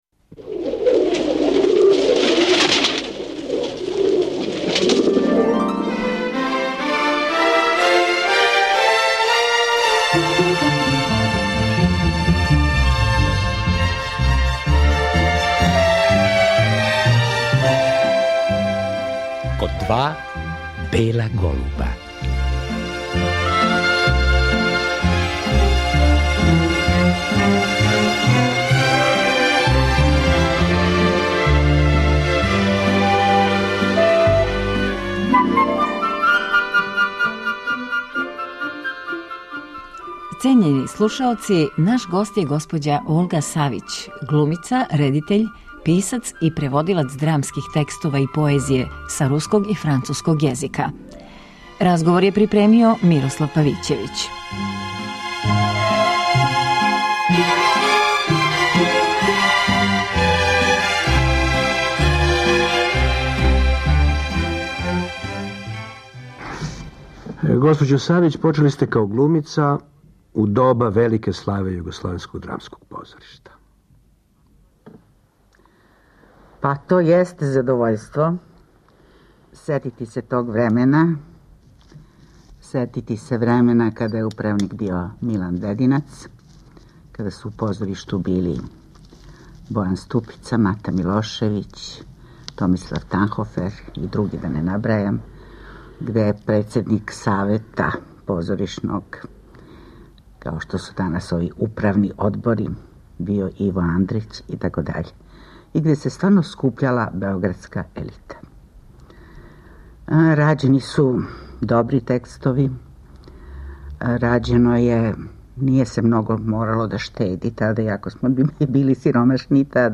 Поводом одласка глумице, емитујемо поново тај разговор.